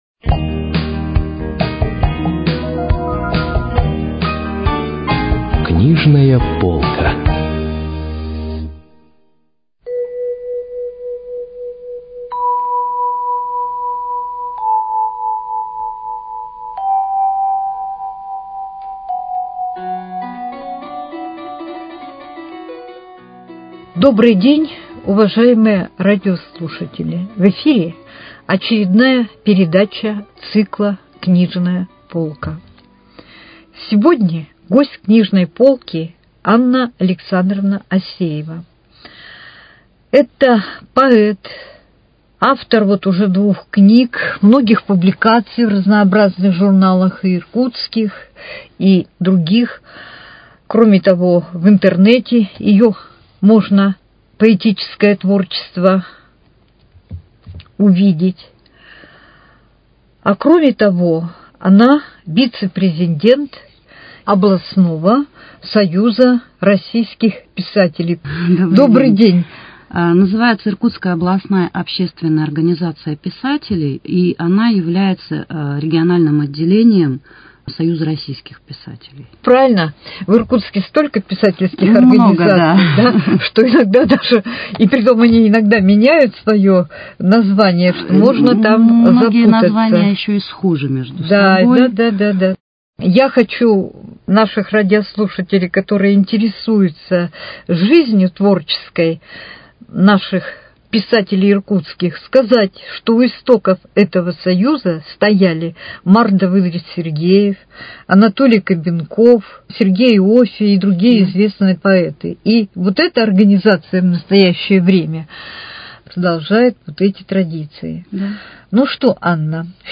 Книжная полка: Беседа
Передача из цикла «Книжная полка».